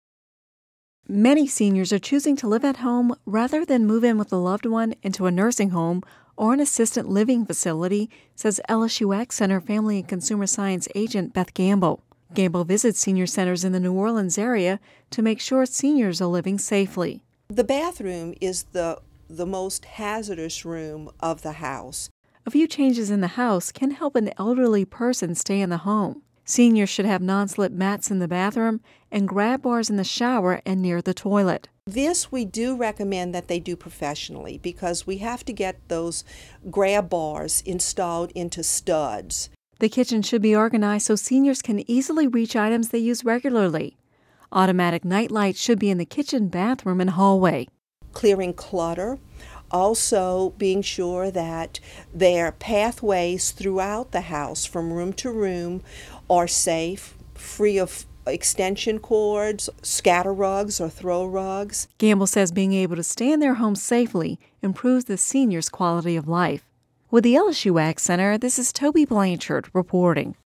(Radio News 02/21/11) Many seniors are choosing to live at home rather than moving in with a loved one or into a nursing home or assisted living facility